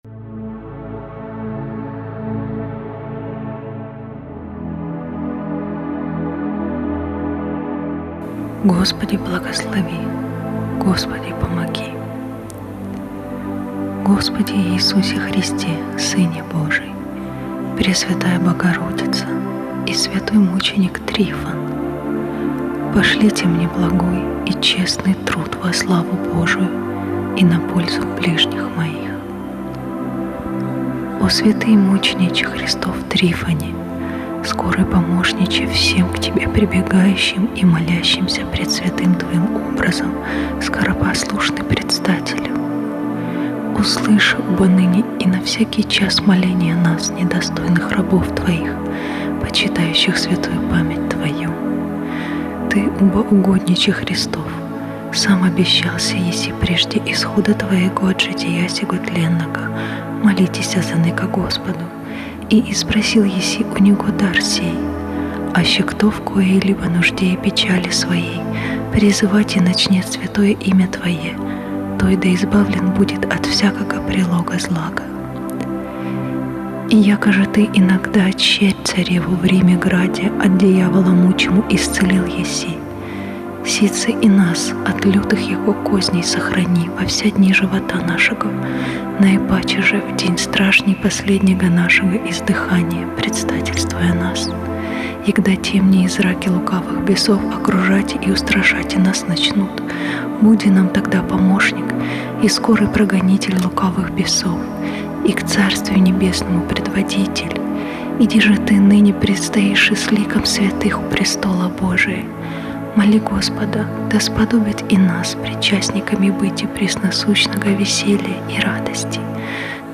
molitva-o-rabote.mp3